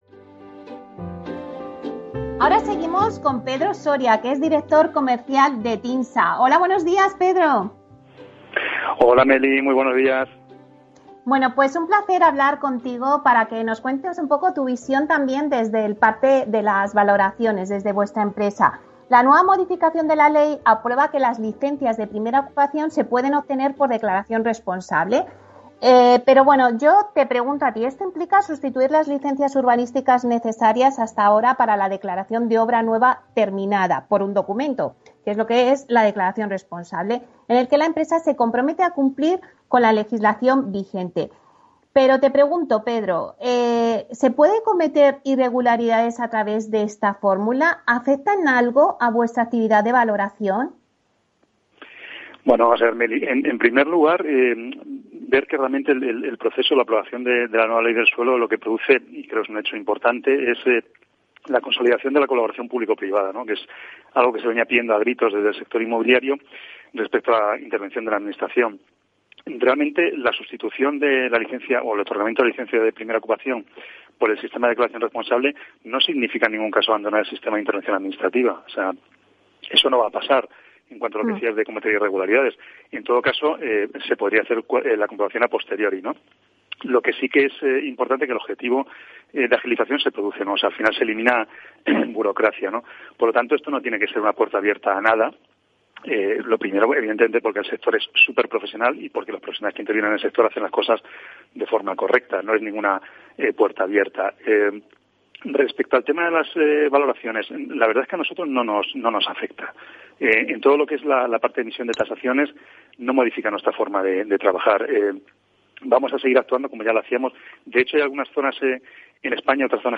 Aquí te dejamos la intervención completa